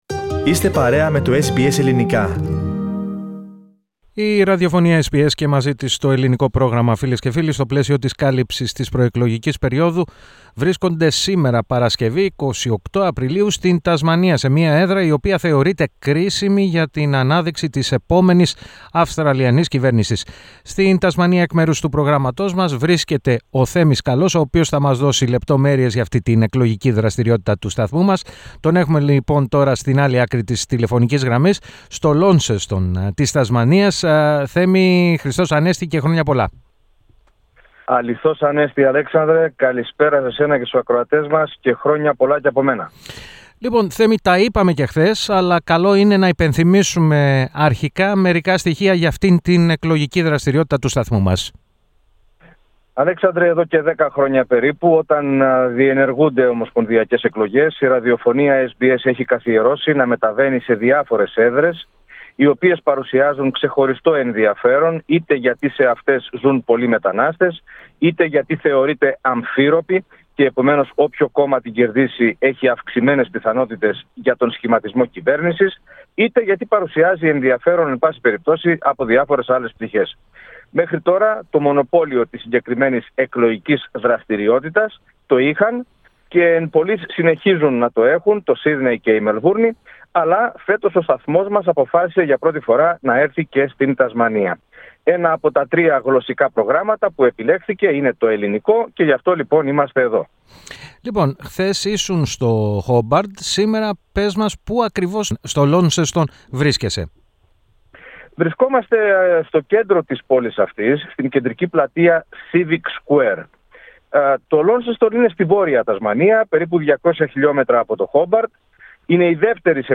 Το Ελληνικό Πρόγραμμα βρέθηκε την Παρασκευή στην κεντρική πλατεία του Launceston, Civic Square.